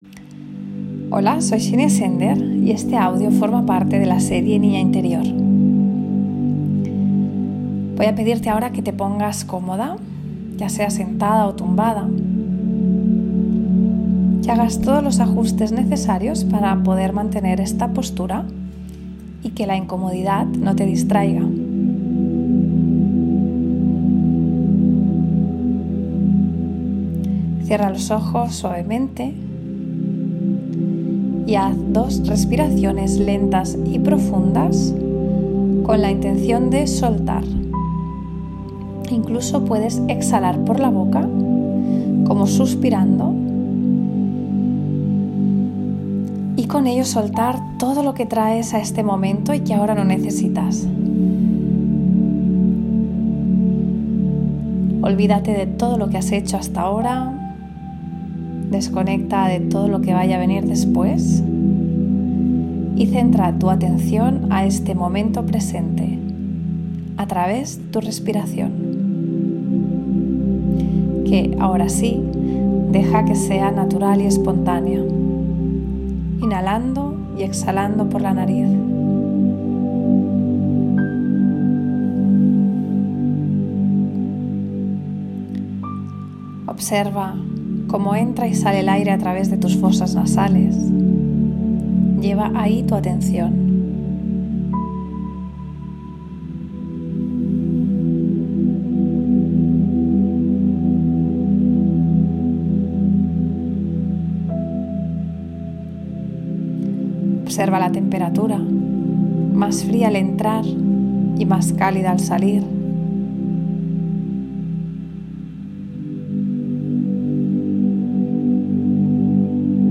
Visualización creativa para conectar con tu esencia.